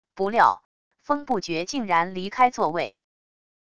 不料……封不觉竟然离开座位wav音频